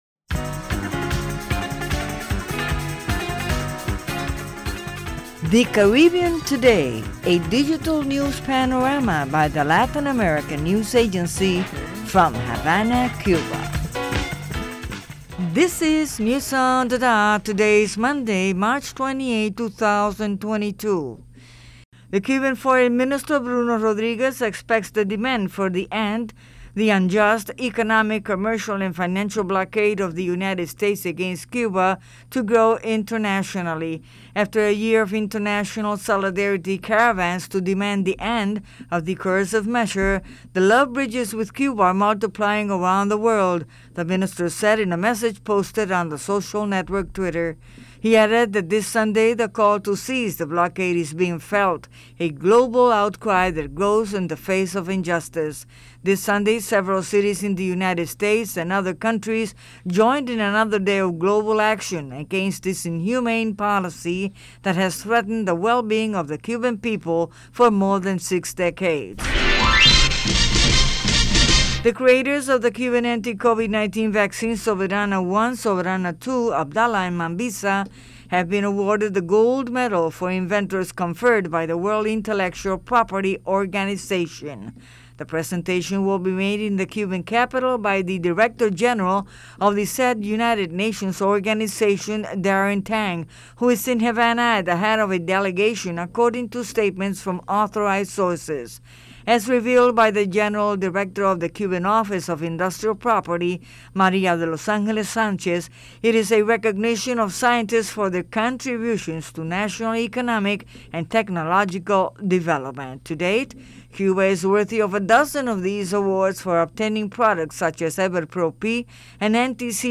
Our information report on the growing rejection of the blockade with caravans of solidarity with Cuba, the awarding by a UN organization to Cuban creators of anti-Covid vaccines, the optimistic prospects for Vietnam-Cuba cooperatioon in construction, Venezuela’s notable reduction in the number of Covid-19 contagions, and the role of Prensa Latina  and Telesur against media warfare